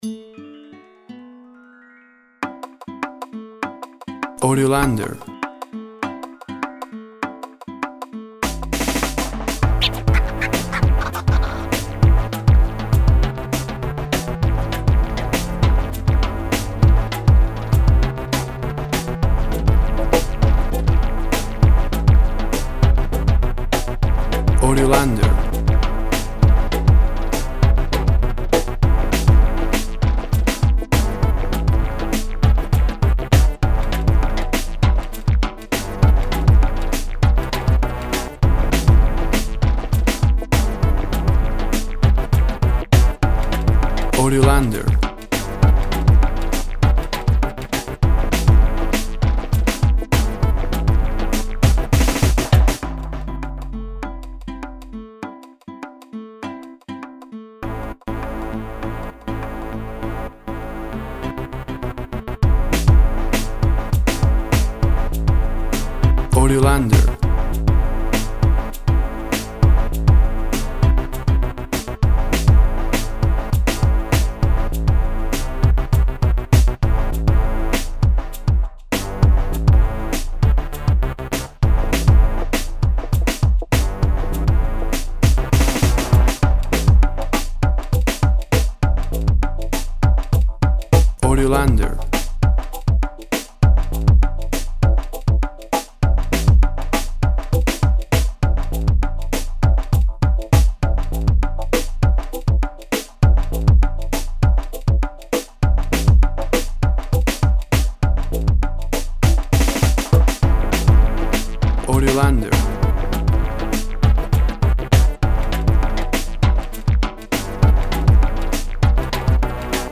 Music latín flow.
Tempo (BPM) 190